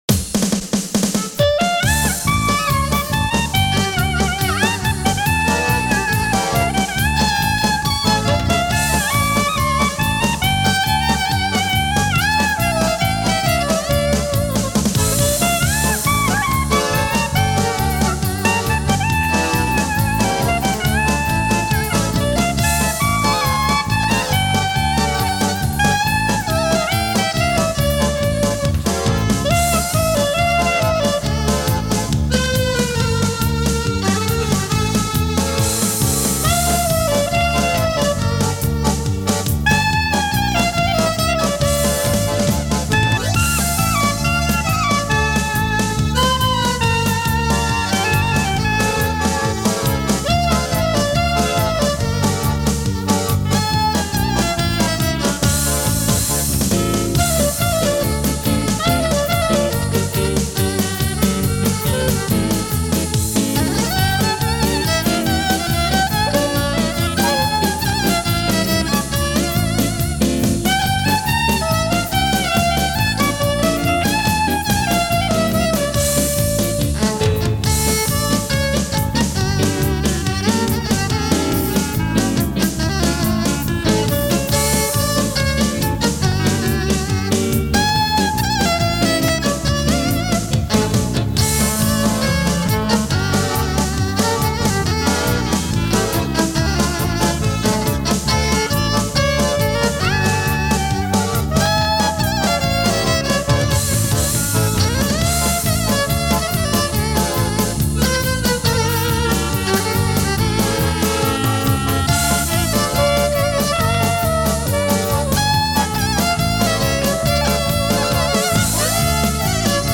Instrumental, Klezmer